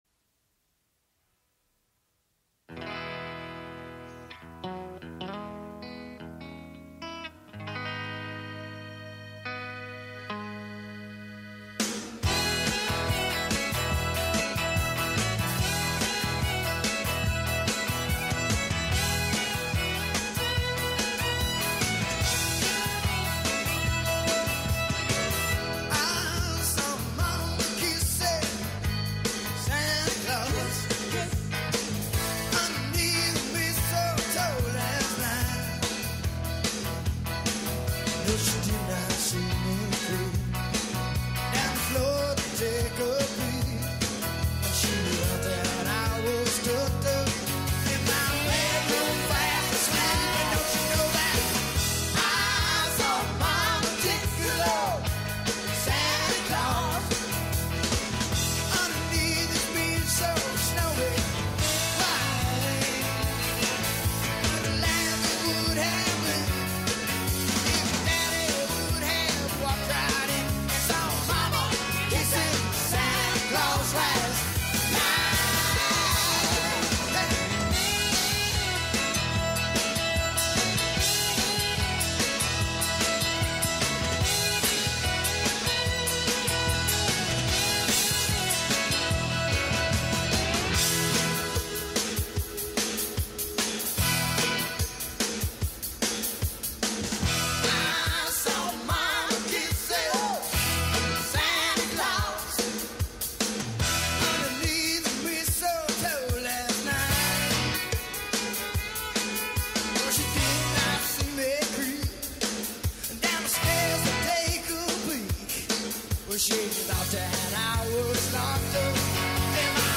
los villancicos más roqueros